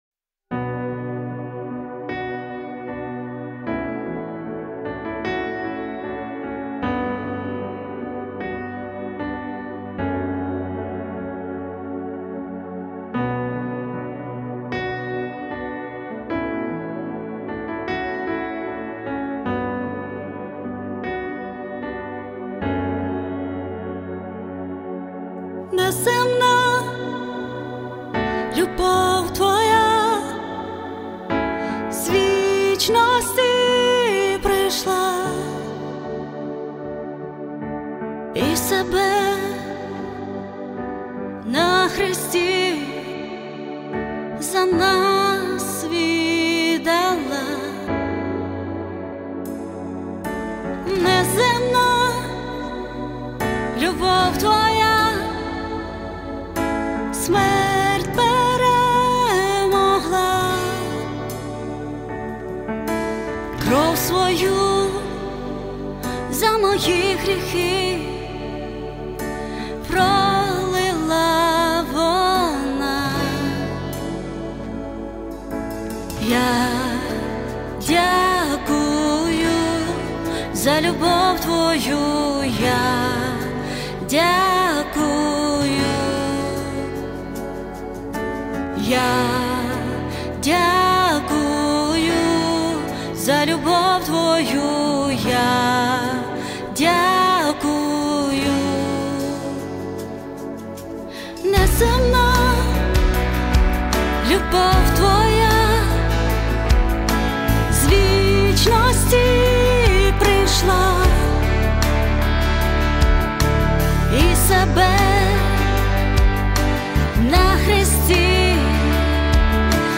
163 просмотра 358 прослушиваний 9 скачиваний BPM: 76